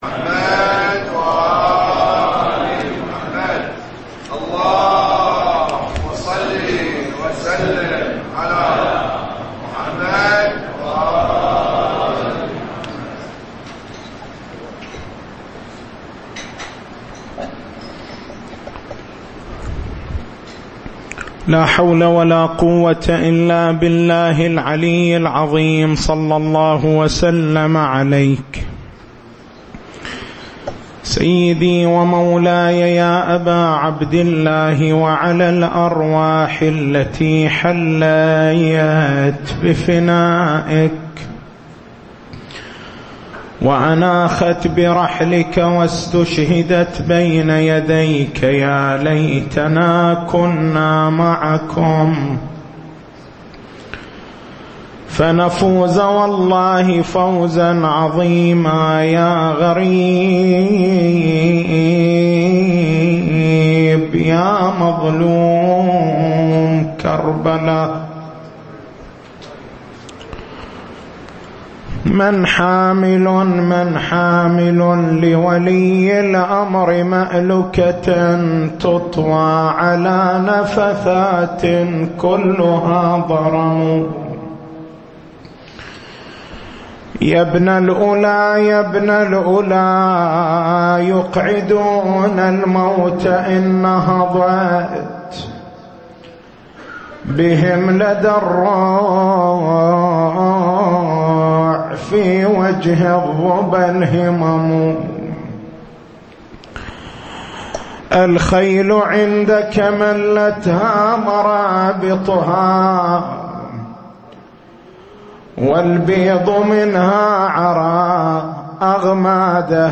تاريخ المحاضرة